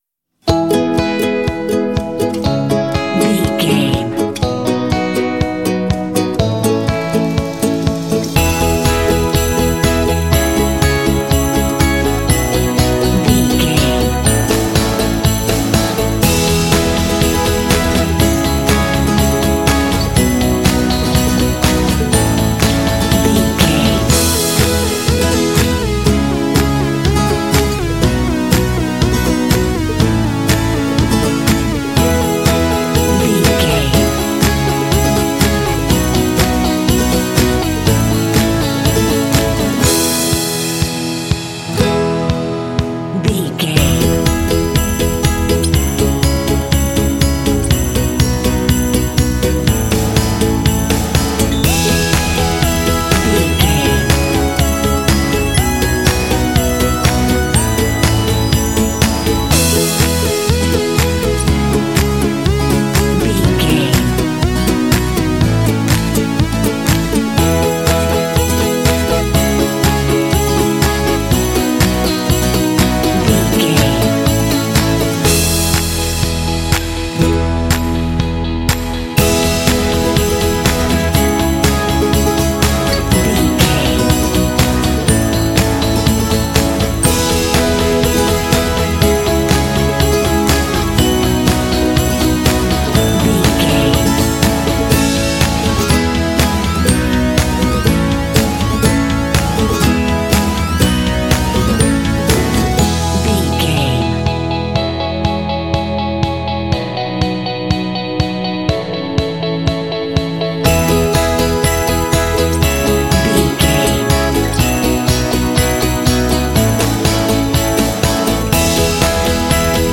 Ionian/Major
happy
uplifting
bouncy
festive
acoustic guitar
piano
drums
synthesiser
contemporary underscore